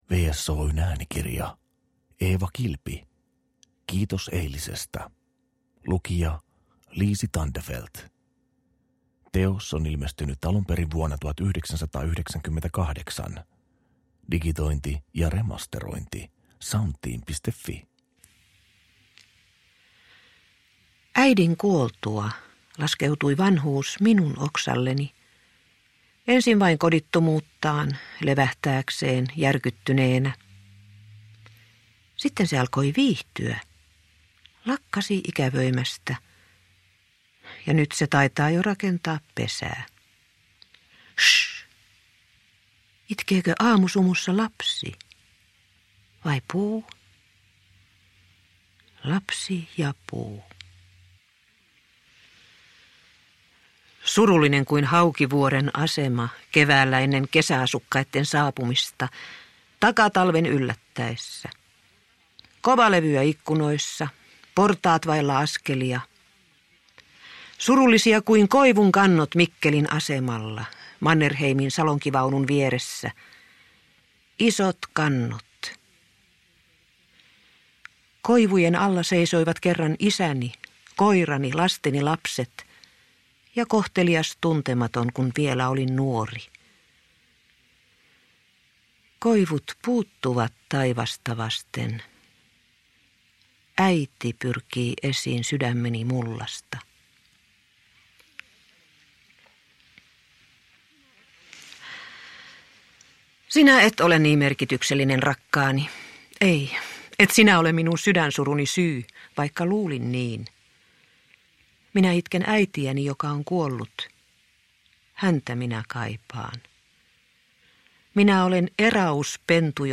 Kiitos eilisestä – Ljudbok – Laddas ner